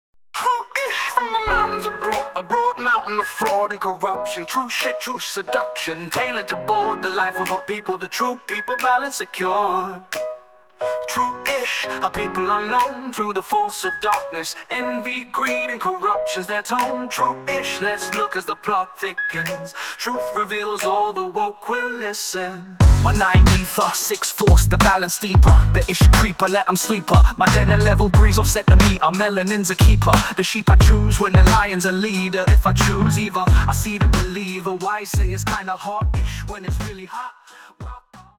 Short version of the song, full version after purchase.
An incredible Funk song, creative and inspiring.